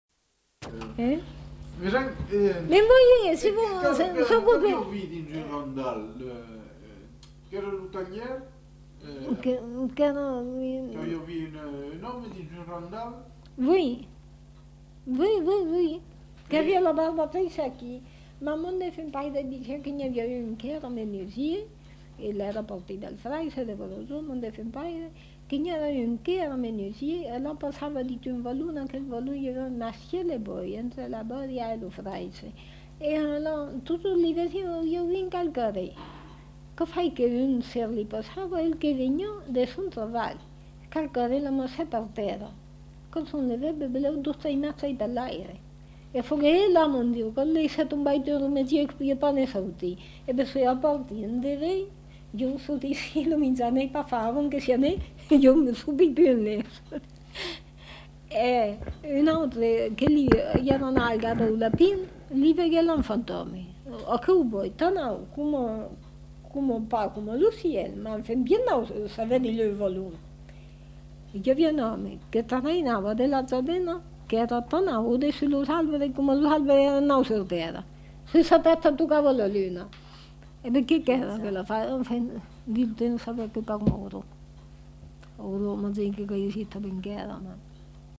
Aire culturelle : Périgord
Lieu : La Chapelle-Aubareil
Genre : conte-légende-récit
Type de voix : voix de femme
Production du son : parlé
Classification : récit de peur